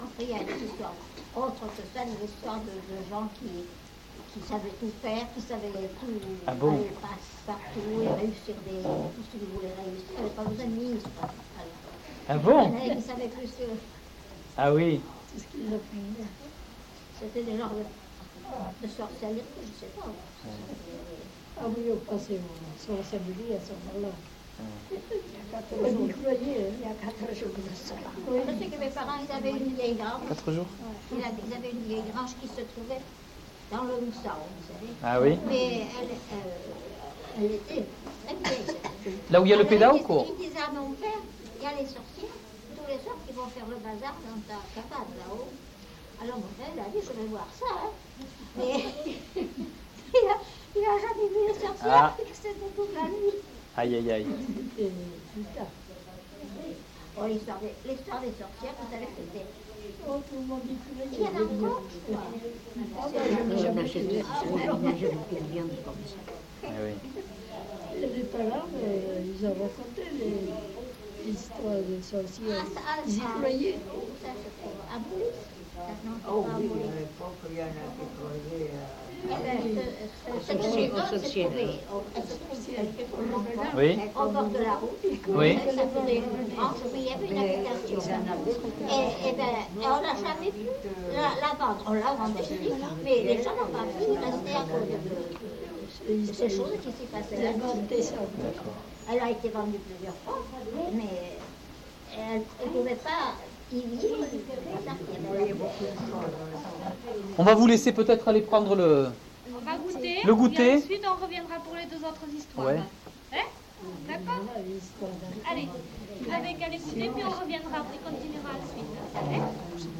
Lieu : Castillon-en-Couserans
Genre : témoignage thématique